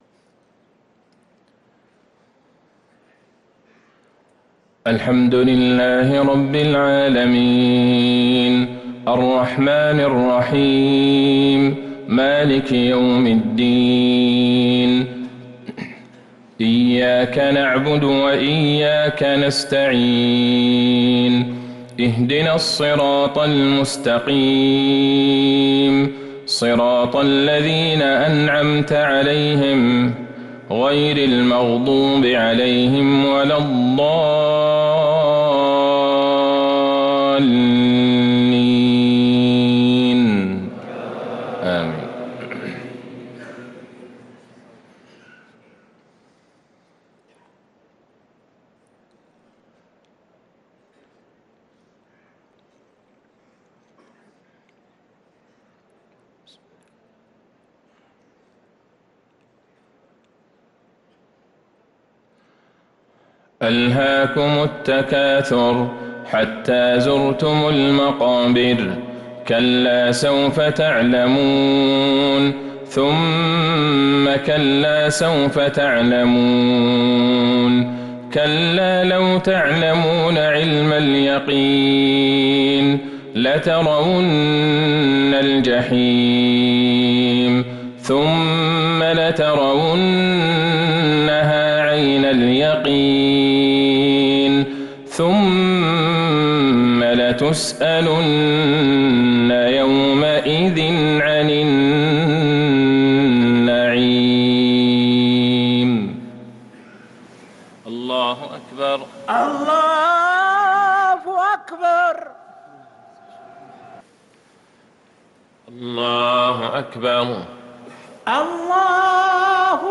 صلاة المغرب للقارئ عبدالله البعيجان 25 محرم 1445 هـ
تِلَاوَات الْحَرَمَيْن .